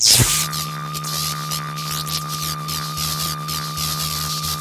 beamretract.wav